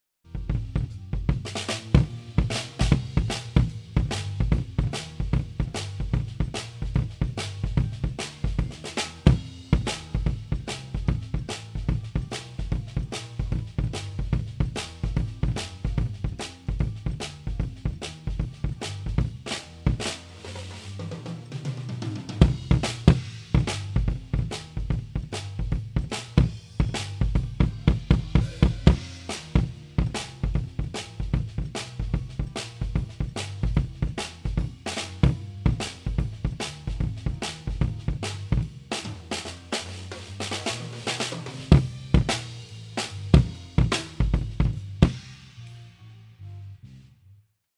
Live-Mitschnitt bei Kneipengig - Bericht mit Audiobeispielen
BD Batter (EV RE27N/D)
Letzteres lieferte einen "kranken" Sound, der nur dazu benutzt wurde, etwas Klick zum Wumms mischen zu können (siehe Soundbeispiele unten).
Hier die Soundbeispiele - die Einzelsignale sind roh und unbearbeitet - die Mischung natürlich schon...
Bassdrum Batter
Bassdrum-Batter_RE27ND.MP3